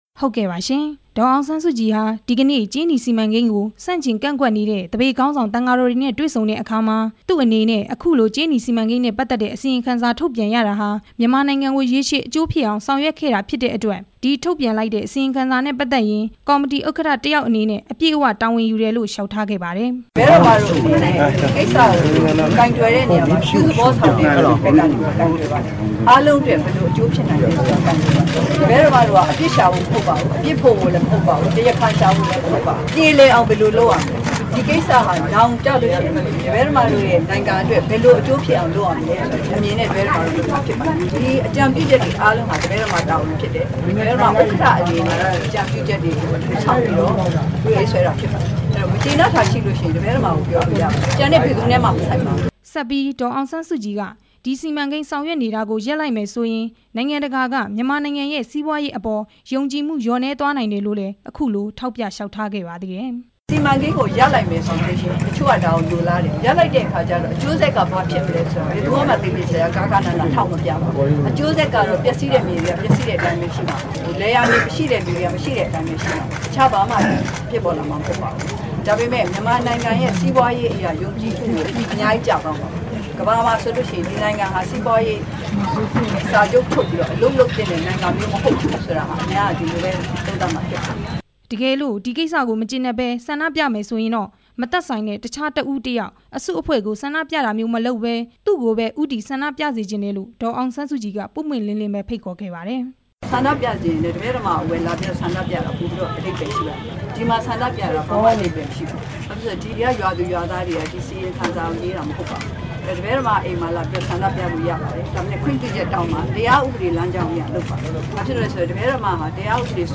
ဒေါ်အောင်ဆန်းစုကြည်ရဲ့ သံဃာတော်တွေကို လျှောက်ထားချက်